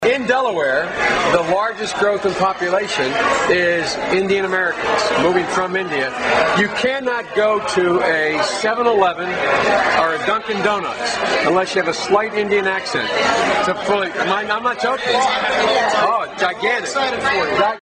Category: Radio   Right: Both Personal and Commercial